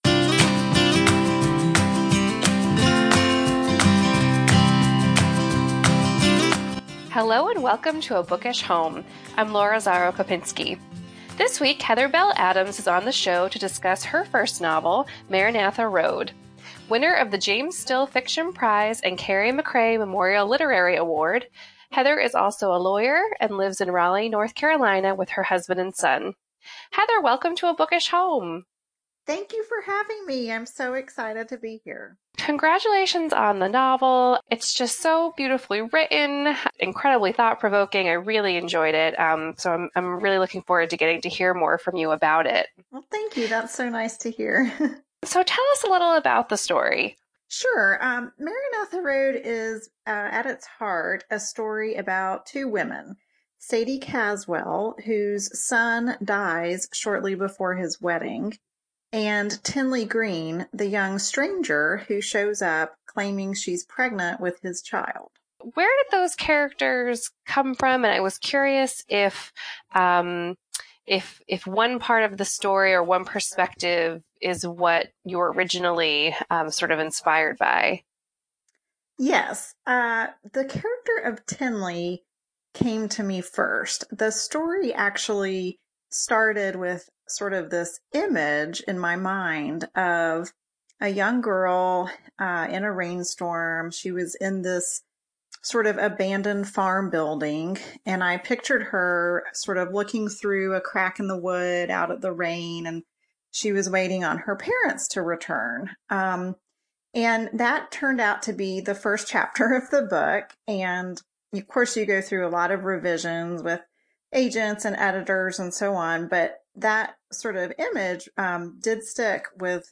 This week, I’m sharing a conversation